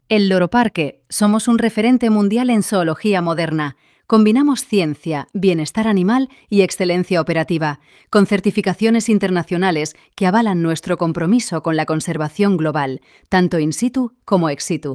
Muestra de voces con IA
Voces femeninas
Locutora de informativos